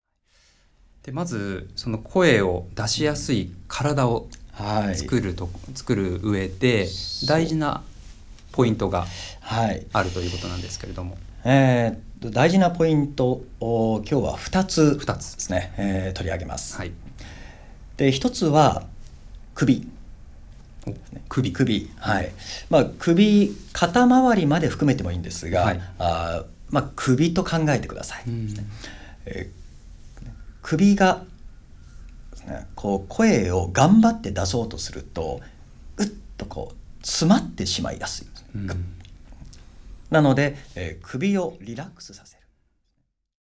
プラスレッスンとして、声の出やすい顔「開いた顔」の音声解説もおこなっています。